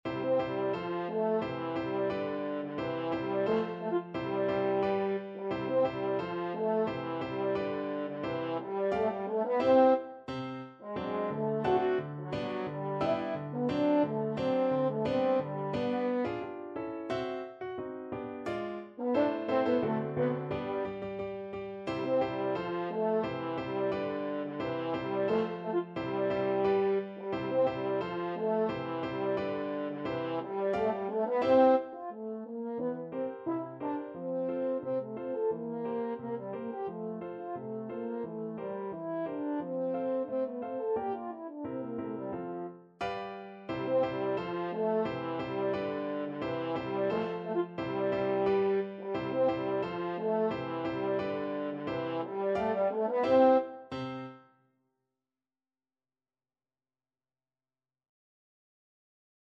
French Horn
C major (Sounding Pitch) G major (French Horn in F) (View more C major Music for French Horn )
2/4 (View more 2/4 Music)
~ = 88 Stately =c.88
Classical (View more Classical French Horn Music)